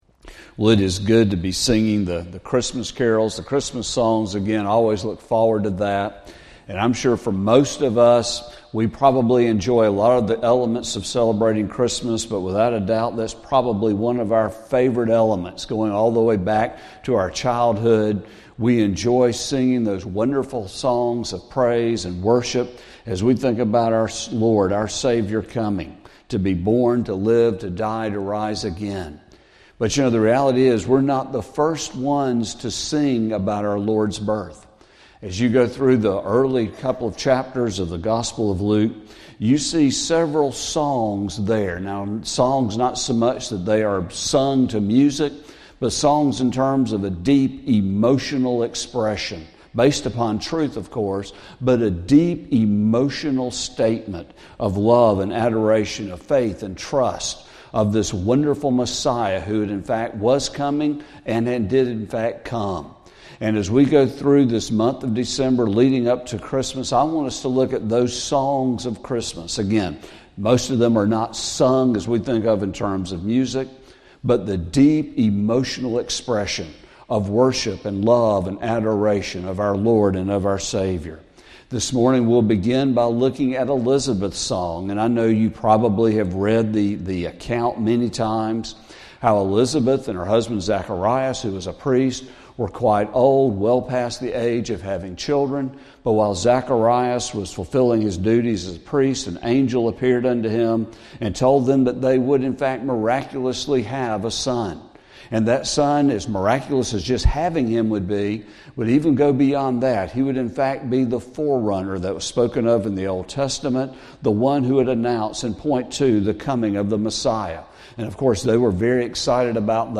Sermon | December 1, 2024